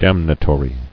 [dam·na·to·ry]